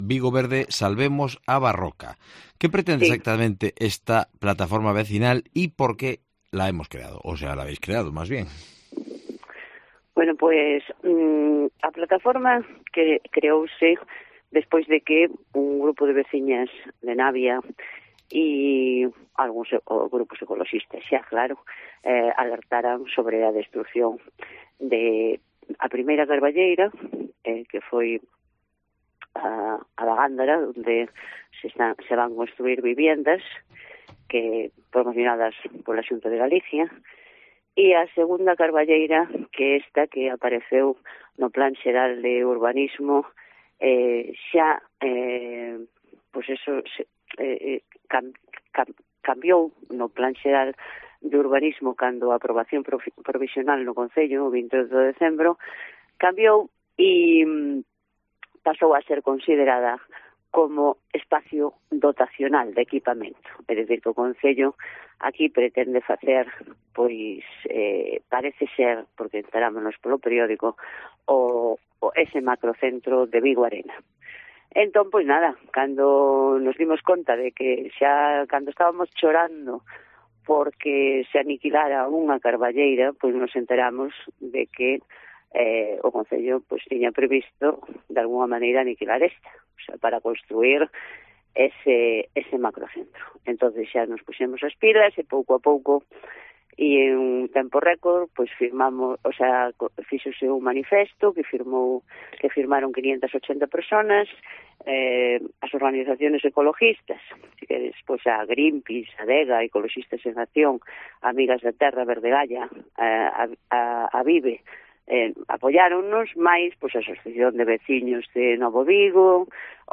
Entrevista con activista contra la tala de la Carballeira de Casaliños para levantar el Vigo Arena